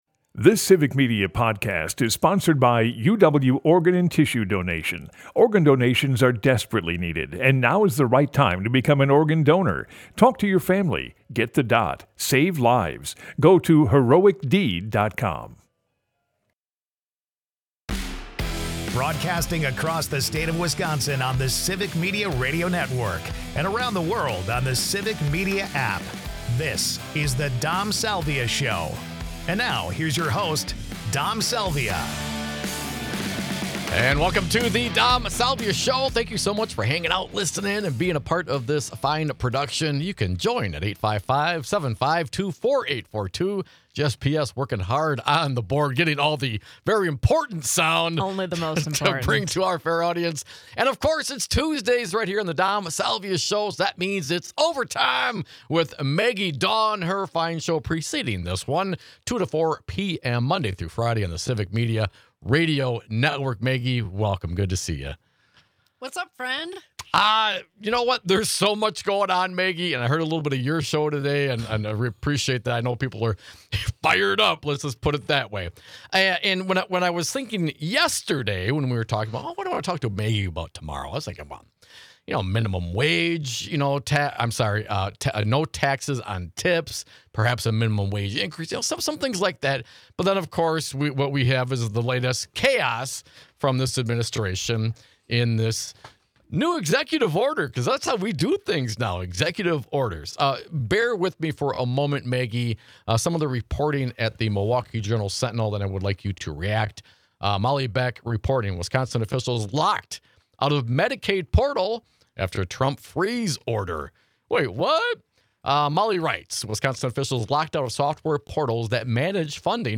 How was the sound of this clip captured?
We're building a state-wide radio network that broadcasts local news